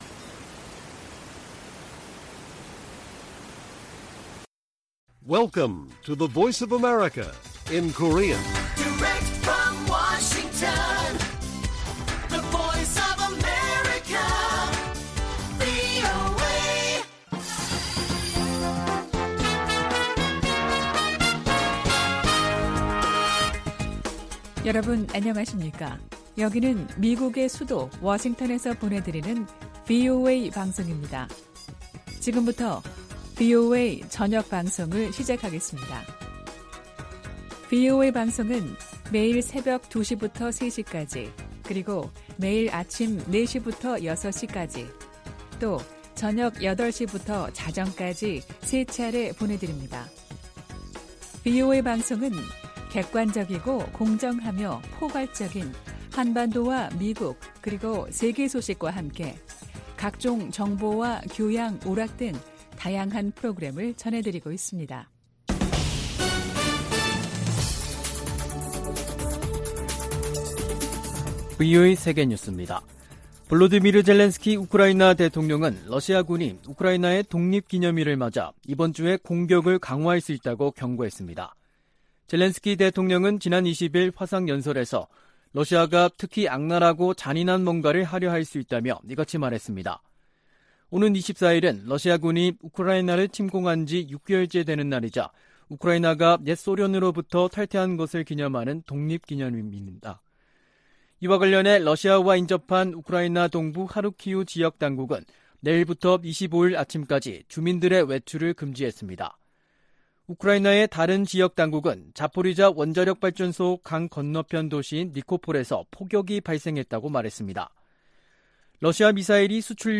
VOA 한국어 간판 뉴스 프로그램 '뉴스 투데이', 2022년 8월 22일 1부 방송입니다. 미군과 한국군이 을지프리덤실드(UFS) 연합훈련을 시작했습니다. 토니 블링컨 미 국무부 장관이 박진 한국 외교부 장관과의 통화에서 대일 관계 개선과 한반도 비핵화 노력에 대한 윤석열 대통령의 광복절 경축사에 감사를 표했다고 국무부가 전했습니다.